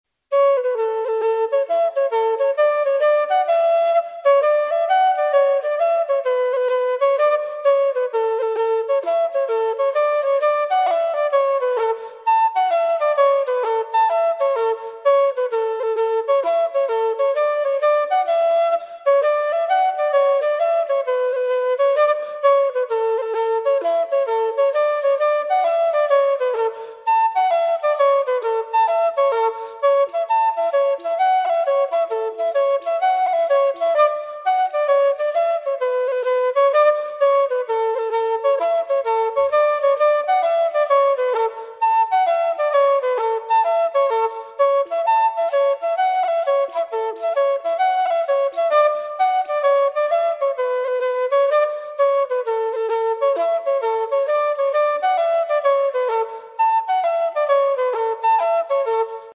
Traditional Irish Music -- Learning Resources McCarthy's (Hornpipe) /